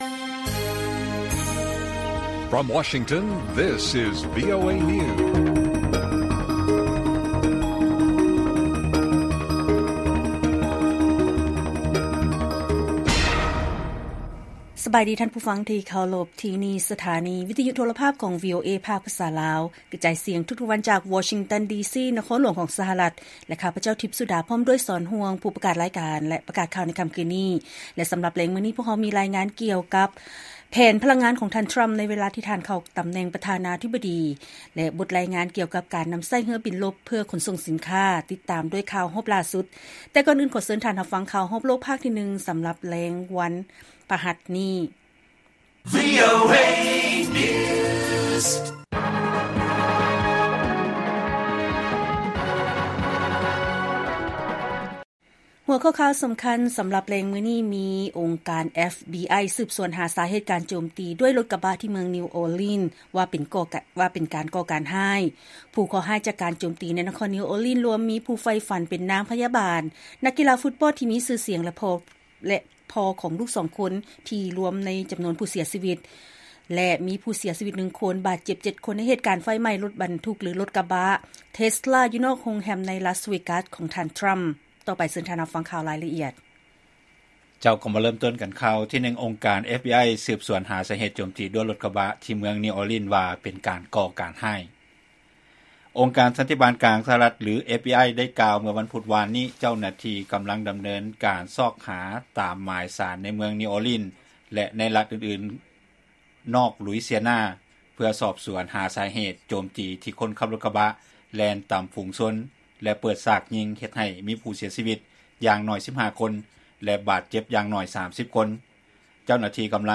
ລາຍການກະຈາຍສຽງຂອງວີໂອເອລາວ: ອົງການ FBI ສືບສວນສາເຫດການໂຈມຕີດ້ວຍລົດກະບະ ທີ່ ນິວ ອໍລີນ ວ່າເປັນການກໍ່ການຮ້າຍ